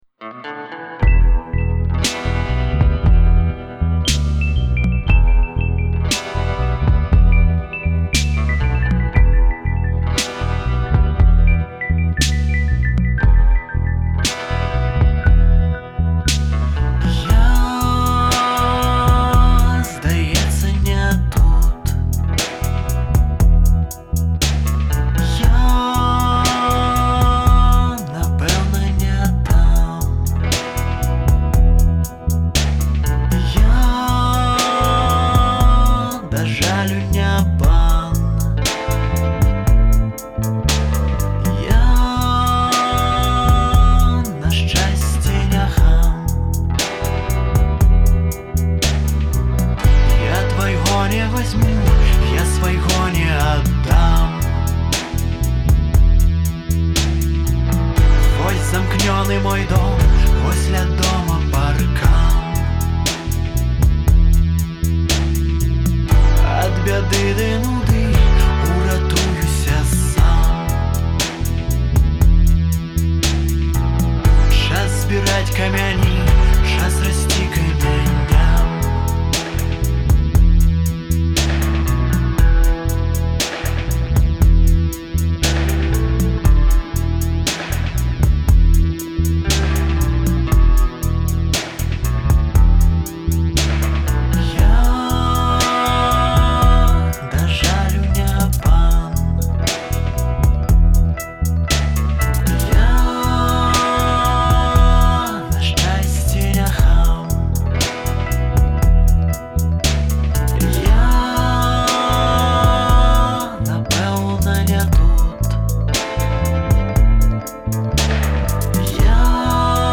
зроблена імі пад уплывам трып-гопу і дабу